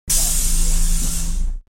دانلود آهنگ اتوبوس 5 از افکت صوتی حمل و نقل
دانلود صدای اتوبوس 5 از ساعد نیوز با لینک مستقیم و کیفیت بالا
جلوه های صوتی